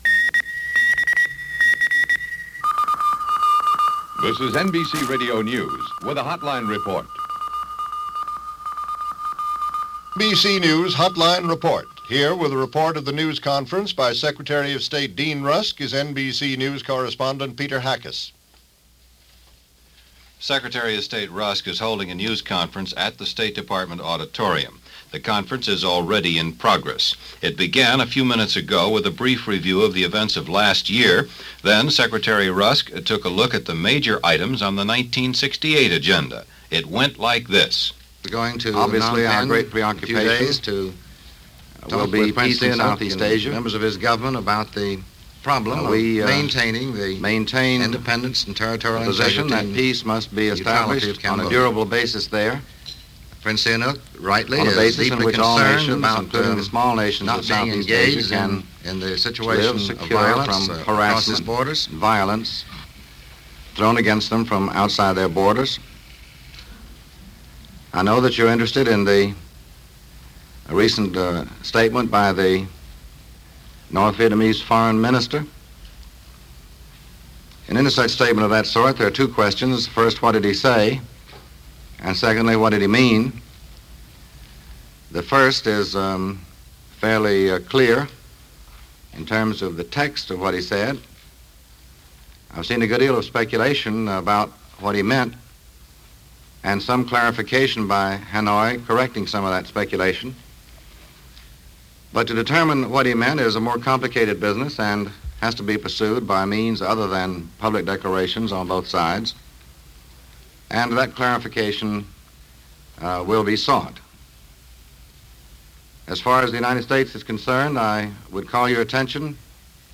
January 4, 1968 - A Dean Rusk News Conference - Press conference from The State Department regarding Southeast Asia and the Middle East.
Secretary of State Dean Rusk, giving a news conference on January 4, 1968. Giving a detailed break down of the previous years events involving the State Department, Rusk discusses our ever-expanding role in Vietnam as well as developments in Southeast Asia in general.
Dean-Rusk-Press-Conf.-Jan.-4-1968.mp3